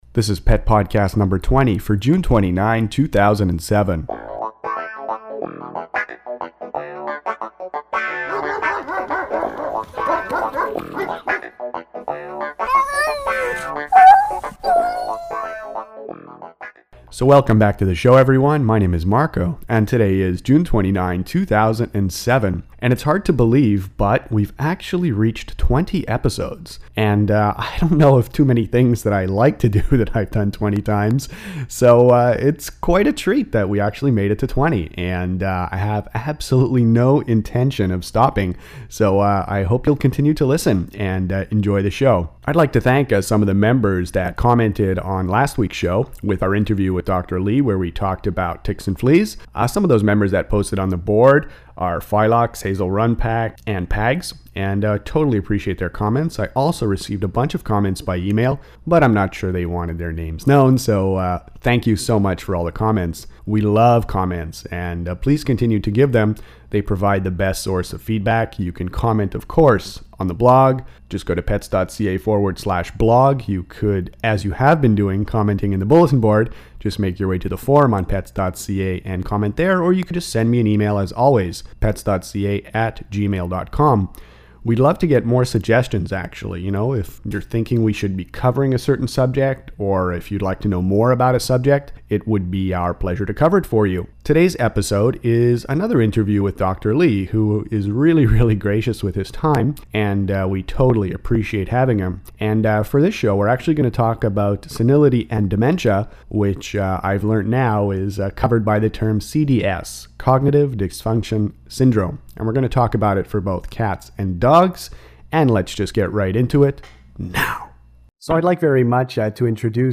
Pet podcast #20 features an interview